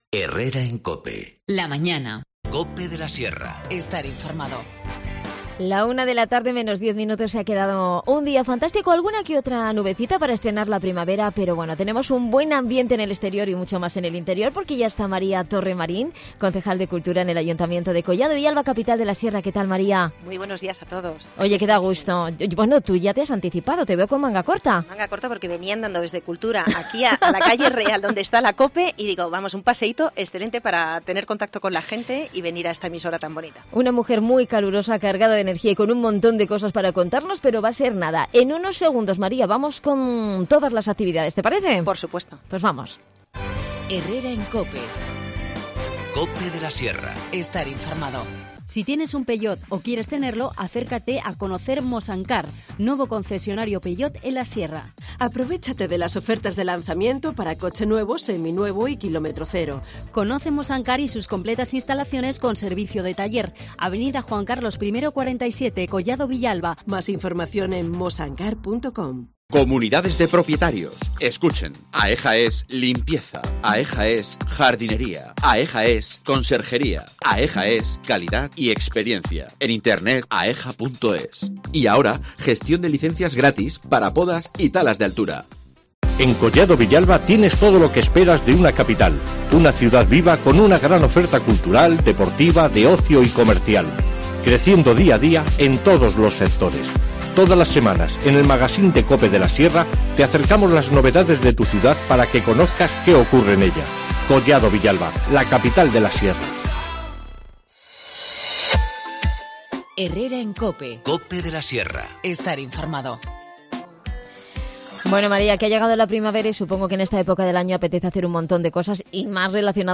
AUDIO: Repasamos con María Torrermarín, concejal de Cultura en Collado Villalba, toda la actualidad del municipio.